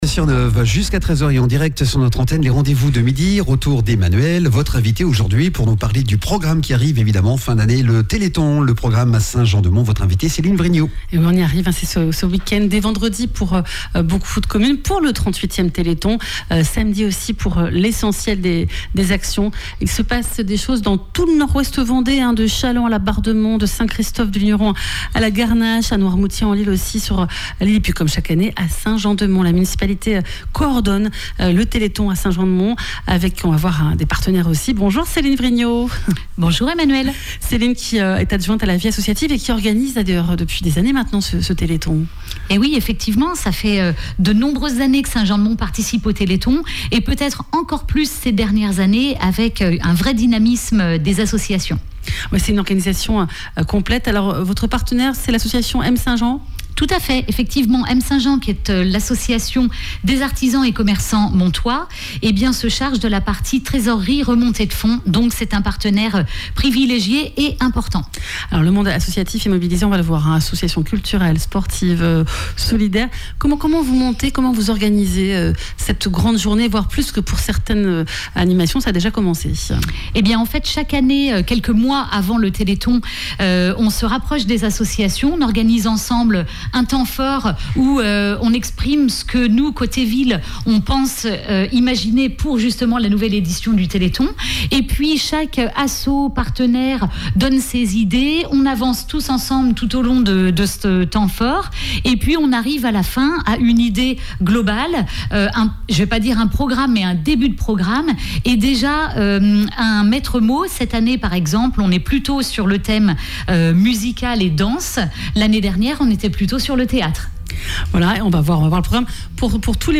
Nous recevons Céline Vrignaud, adjointe à la vie associative.